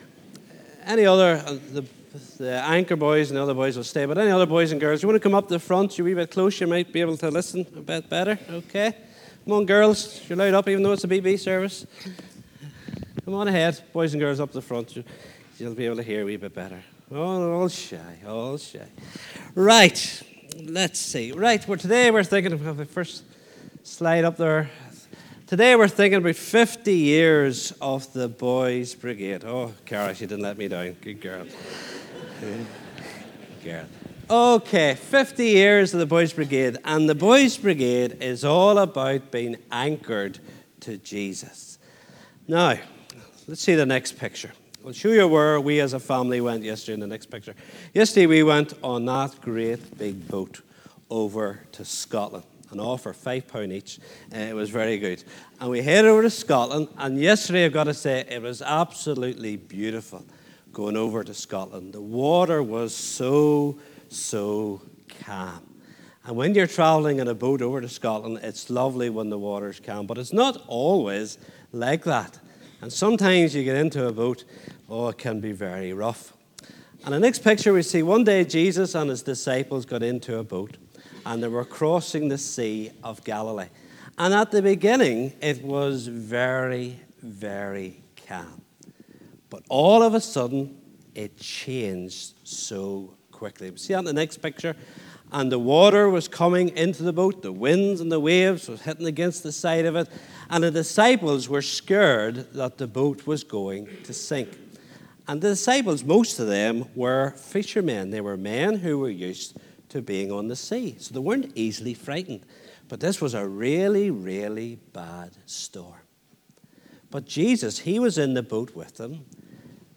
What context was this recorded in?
2nd Ahoghill BB 50th Anniversary Service